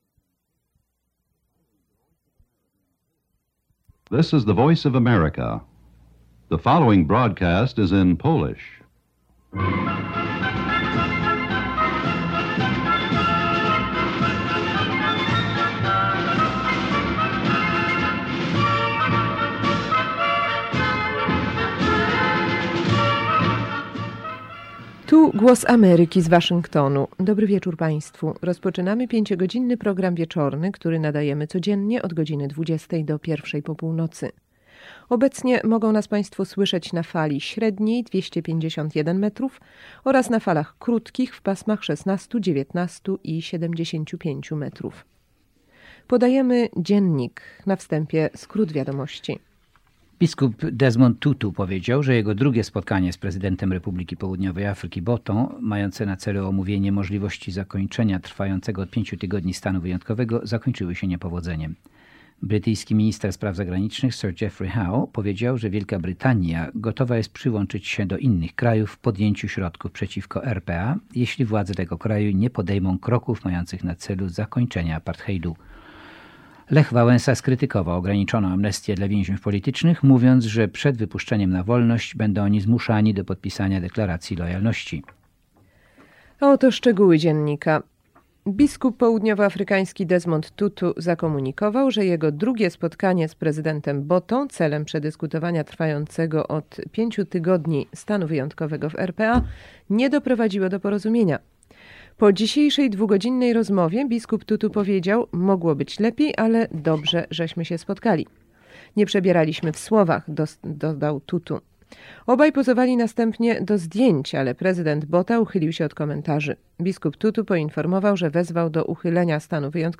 Unikalne nagranie audycji Sekcji Polskiej Głosu Ameryki z 21 lipca 1986 r. (14:00 Waszyngton / 20:00 Warszawa) – świadectwo odrodzonej redakcji polskiej w czasach zimnej wojny.
Pudełko z taśmą szpulową z nagraniem audycji Sekcji Polskiej Głosu Ameryki z 21 lipca 1986 r., godz. 14:00 czasu waszyngtońskiego / 20:00 czasu warszawskiego, przygotowanym dla amerykańskiej Narodowej Agencji Bezpieczeństwa (NSA).